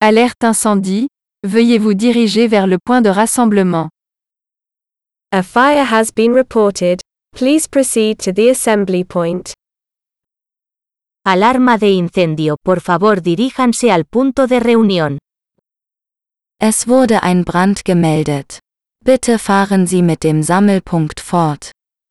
Examples of Voice Messages